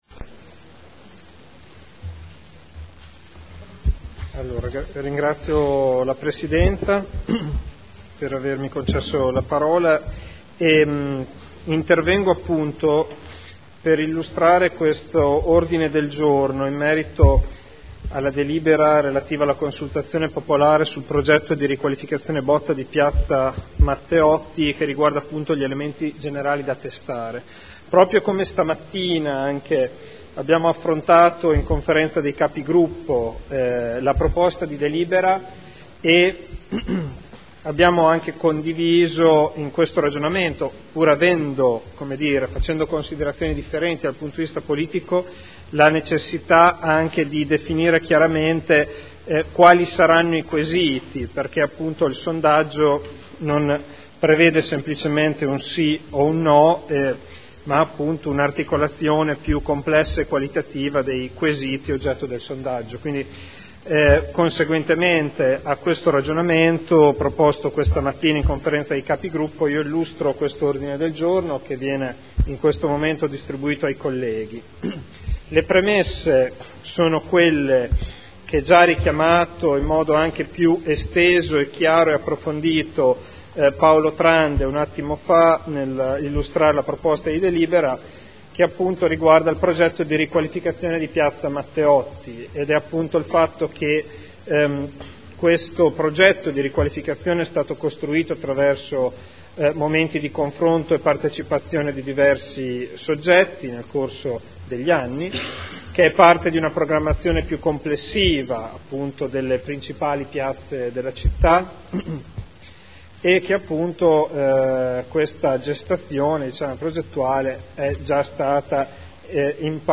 Seduta del 05/09/2011. Consultazione popolare ai sensi dell’art. 8 del Regolamento degli Istituti di Partecipazione del Comune di Modena sul progetto di ristrutturazione di Piazza Matteotti mediante sondaggio (Conferenza Capigruppo del 5 settembre 2011). Illustra Ordine del giorno.